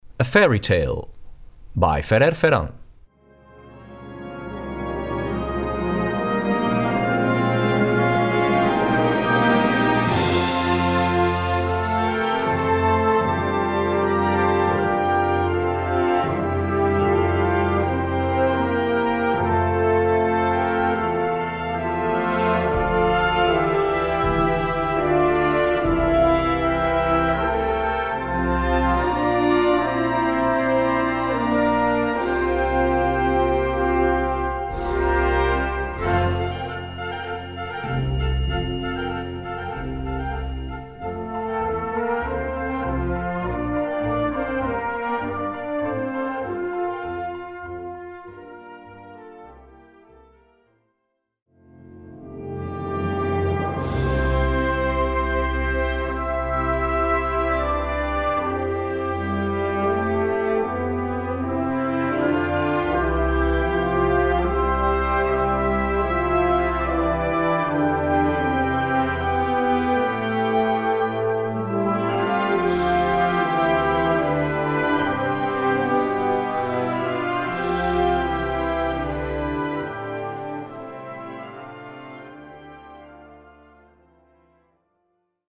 Gattung: Fantasia Magica
Besetzung: Blasorchester